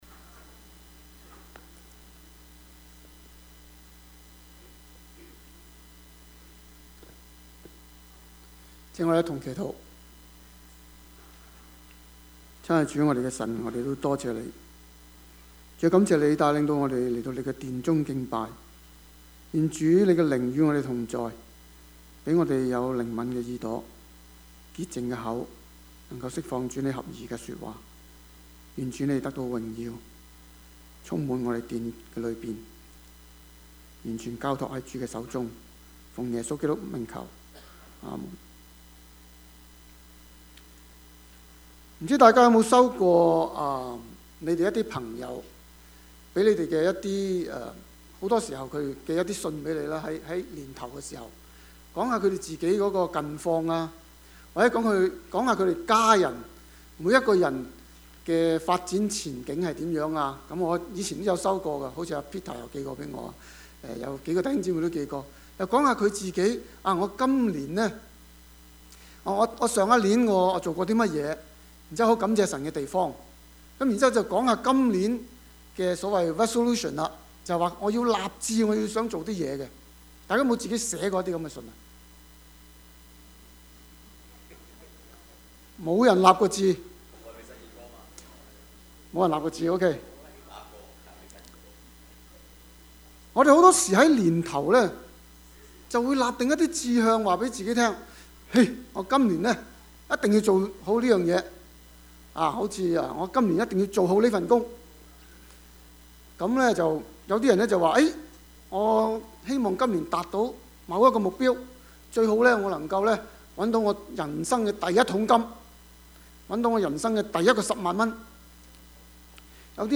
Service Type: 主日崇拜
Topics: 主日證道 « 我們都是這些事的見證 聖經和基督的模範 »